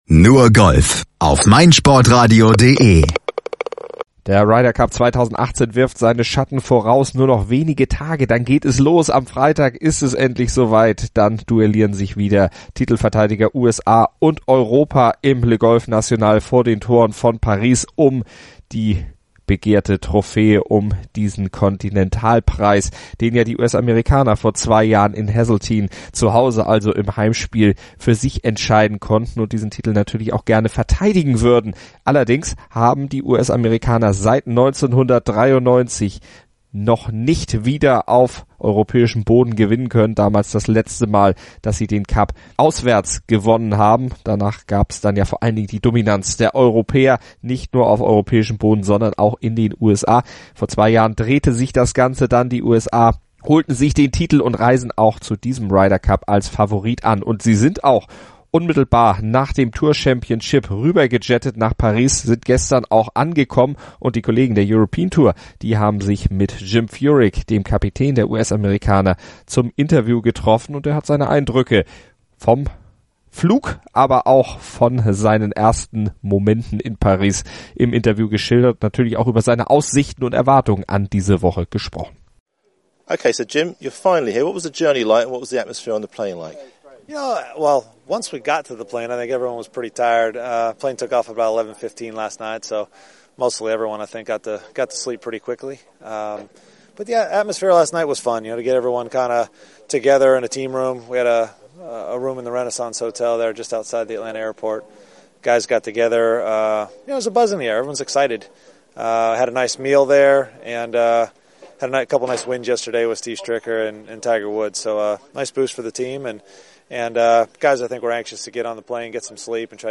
Ryder Cup: Die Captain-Interviews ~ Nur Golf Podcast